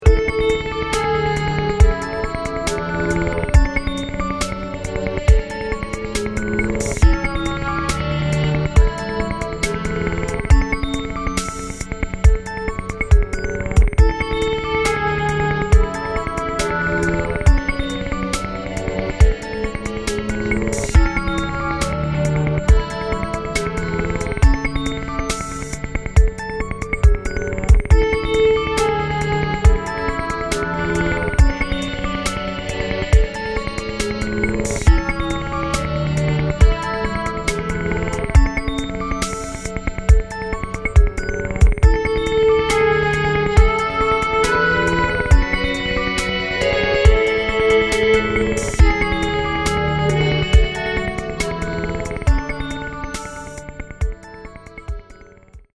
クリアな音が僕達の五感を刺激します。浮遊感のファンタジックマジック！！！